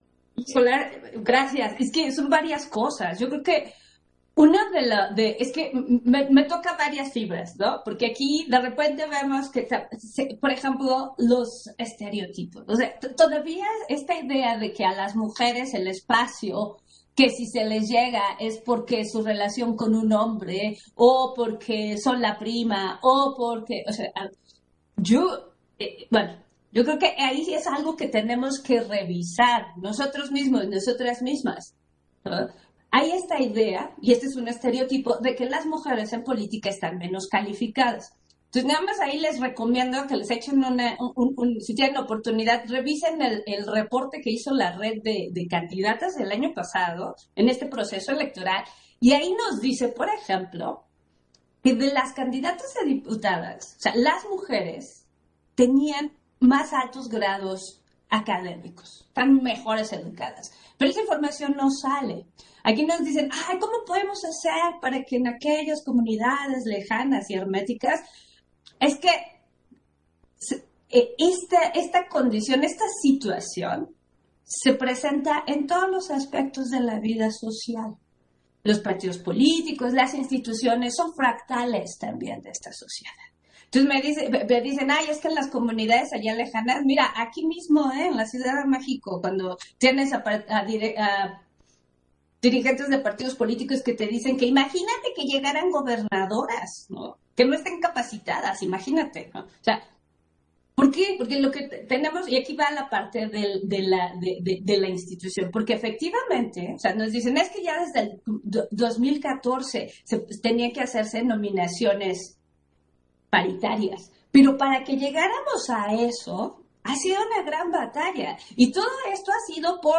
Intervención de Norma De La Cruz, en la segunda mesa de diálogo, Derechos políticos y electorales de las mujeres jóvenes